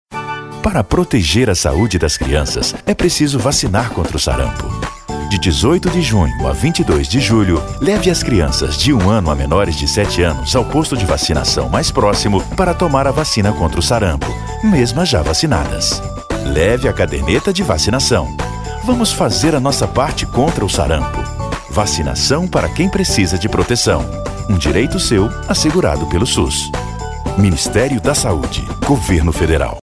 DMVoz Produções - Locução profissional